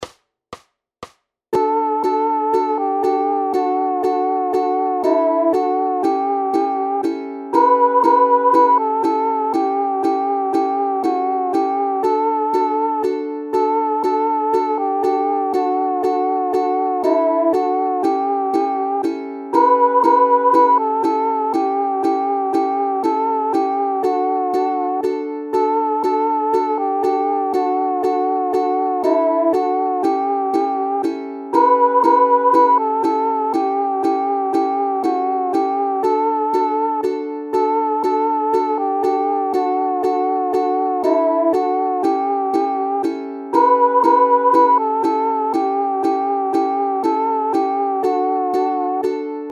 KE VŠEM AKORDŮM A PÍSNÍM JSOU PŘIPOJENY ZVUKOVÉ DEMONSTRAČNÍ UKÁZKY.
01-Marjanko Marjanko UKE.mp3